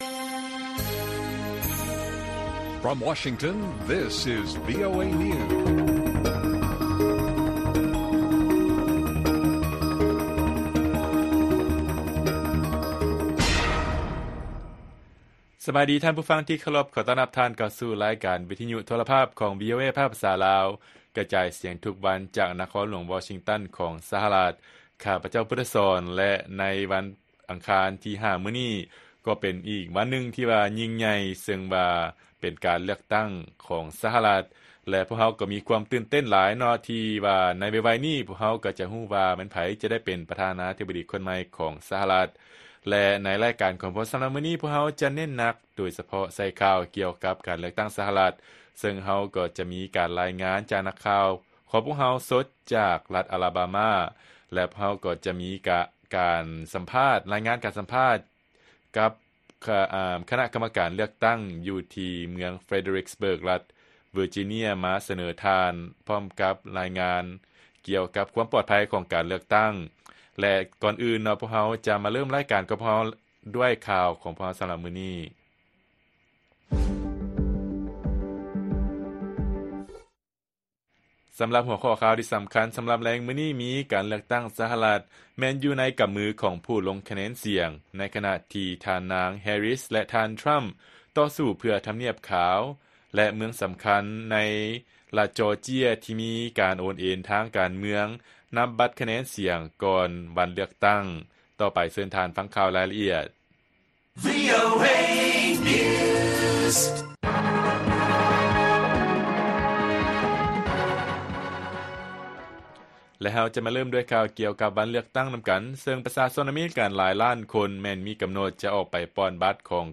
ລາຍການກະຈາຍສຽງຂອງວີໂອເອລາວ: ການເລືອກຕັ້ງ ສະຫະລັດ ແມ່ນຢູ່ໃນກຳມືຂອງຜູ້ລົງຄະແນນສຽງ ໃນຂະນະທີ່ທ່ານນາງ ແຮຣິສ ແລະ ທ່ານ ທຣຳ ຕໍ່ສູ້ເພື່ອທຳນຽບຂາວ
ວີໂອເອພາກພາສາລາວ ກະຈາຍສຽງທຸກໆວັນ